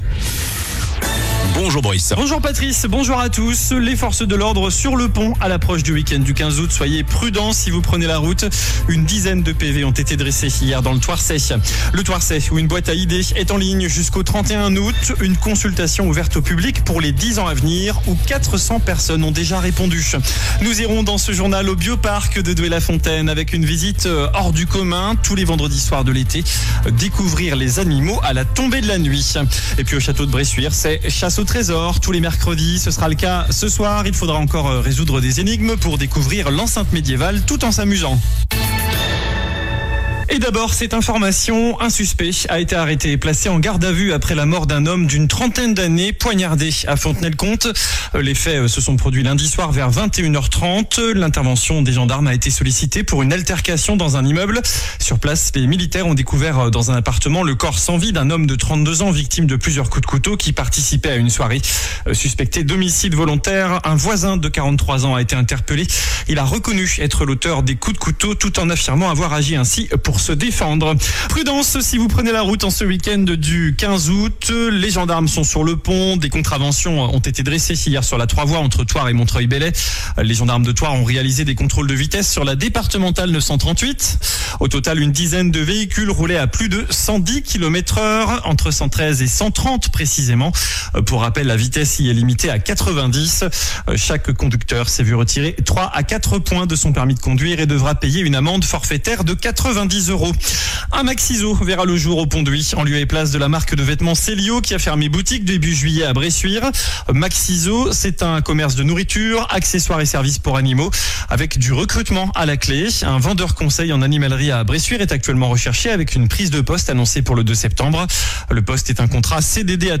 JOURNAL DU MERCREDI 14 AOÛT ( MIDI )